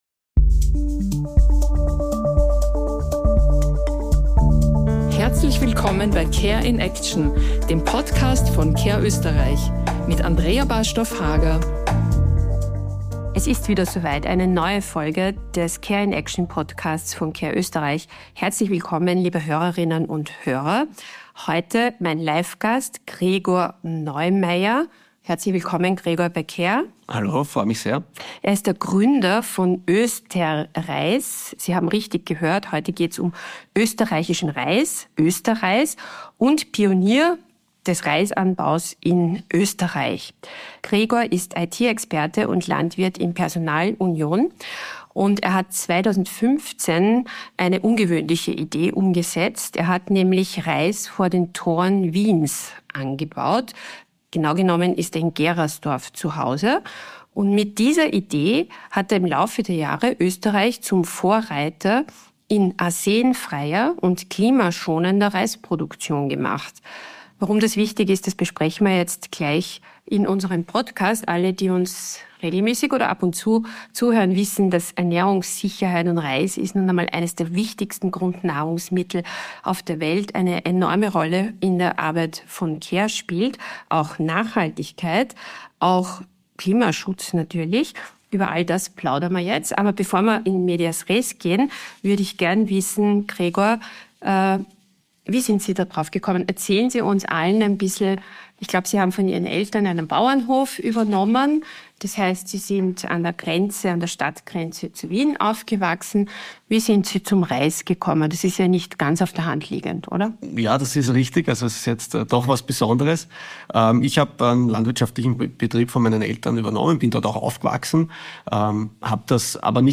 Ein Gespräch über globale Herausforderungen und lokale Lösungen.